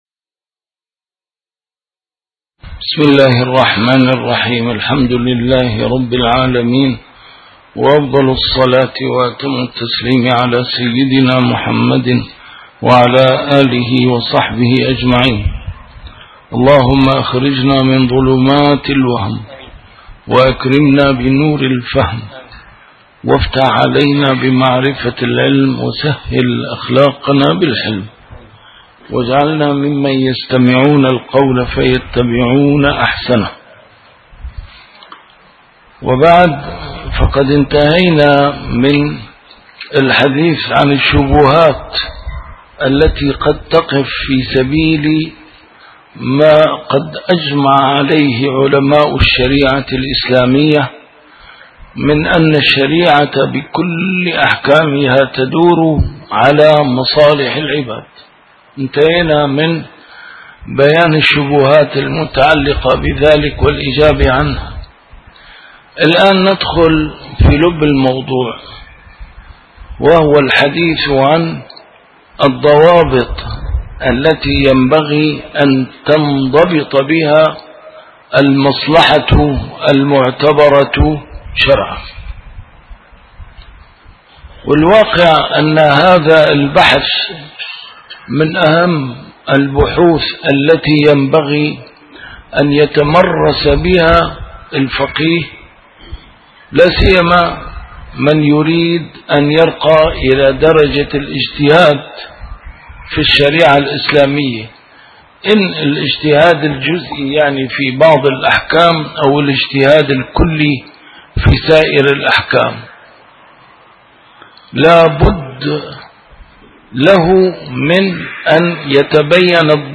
A MARTYR SCHOLAR: IMAM MUHAMMAD SAEED RAMADAN AL-BOUTI - الدروس العلمية - ضوابط المصلحة في الشريعة الإسلامية - الباب الثاني: ضوابط المصلحة الشعرية: تمهيد (107)الضابط الأول: اندراجها في مقاصد الشارع (110) أنواع المقاصد والتمثيل لها (110) حتى بداية عنوان: وأما التحسينيات (111)